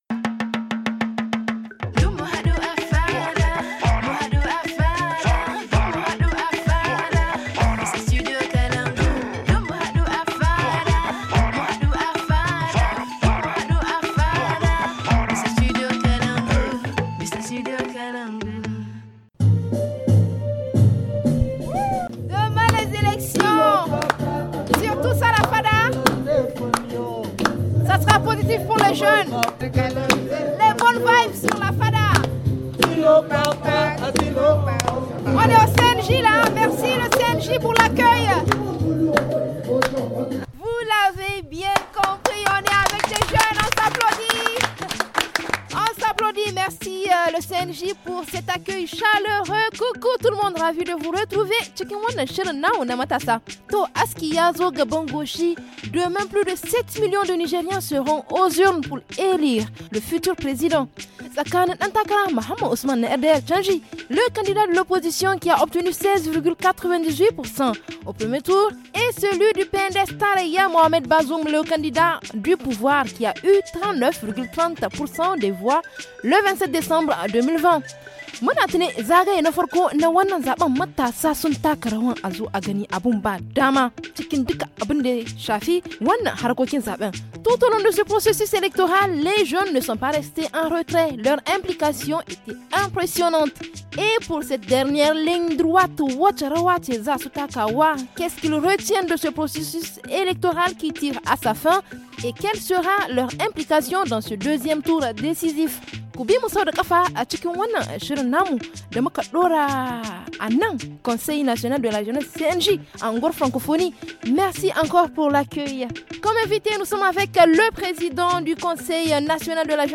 On en parle au siège du conseil national de la jeunesse (CNJ) à Niamey.